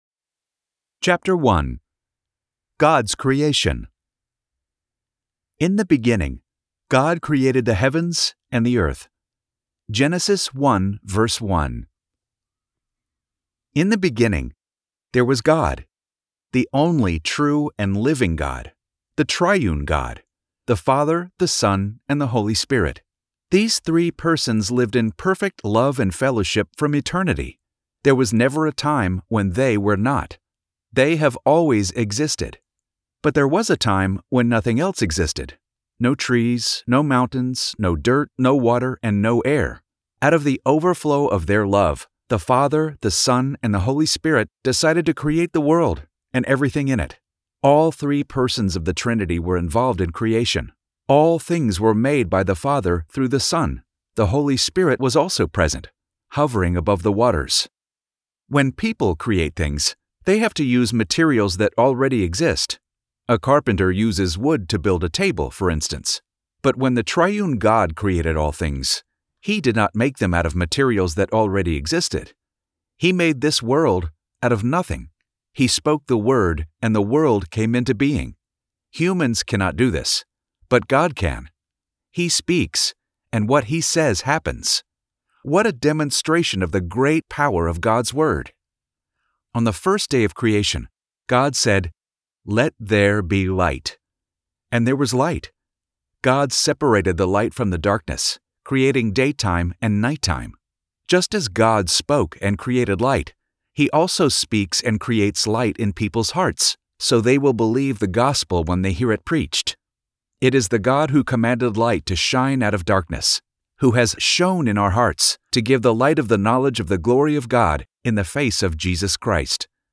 Audiobook Download